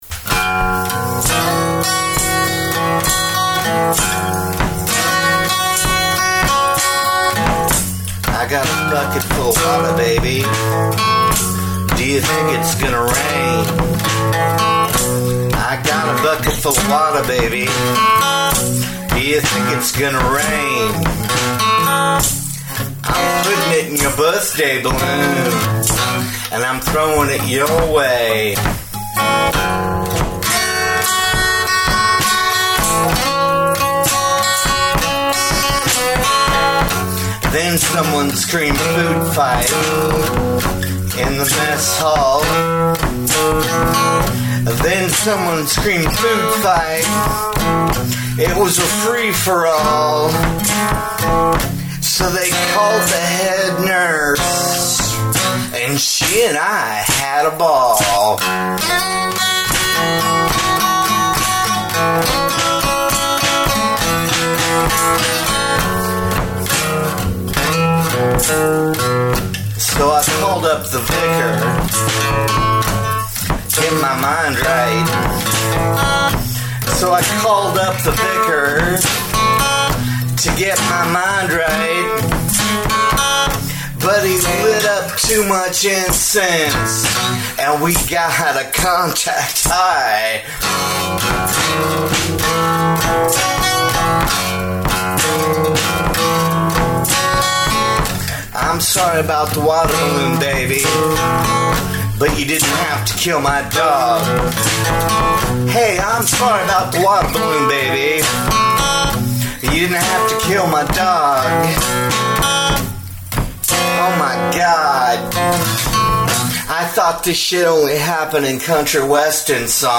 Original Music